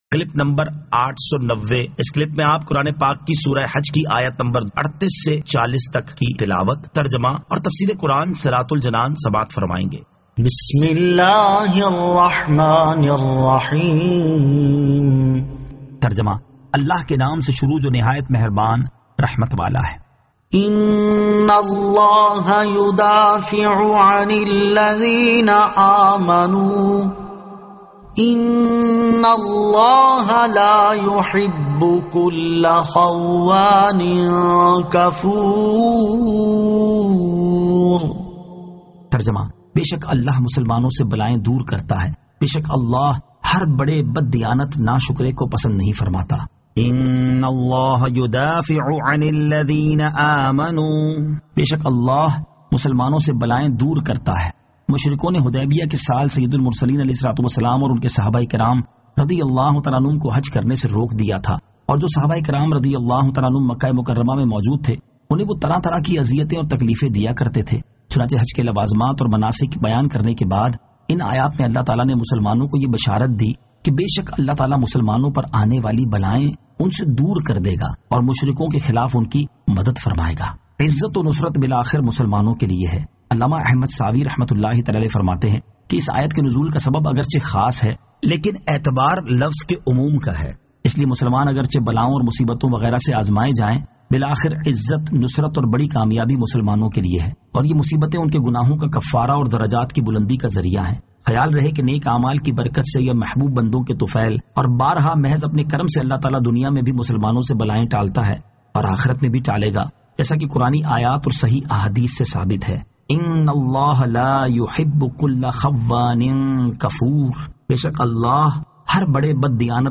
Surah Al-Hajj 38 To 40 Tilawat , Tarjama , Tafseer